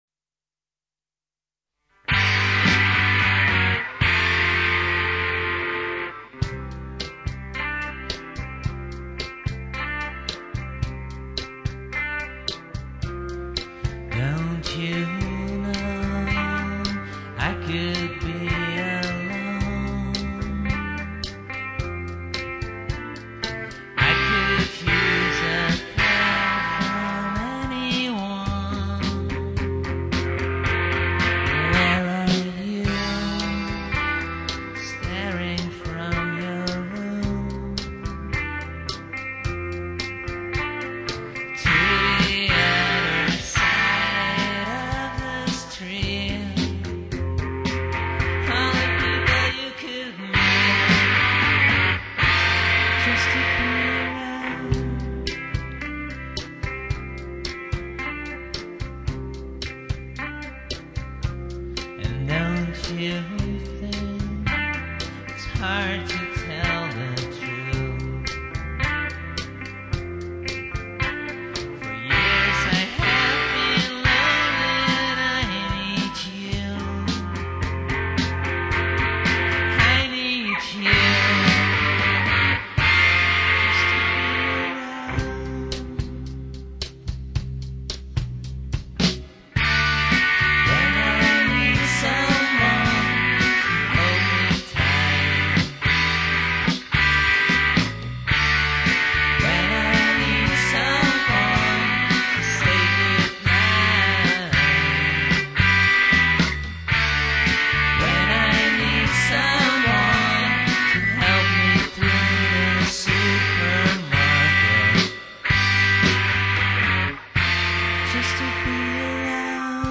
where: recorded at CMA (Amsterdam)
the rock&roll version
we love reverb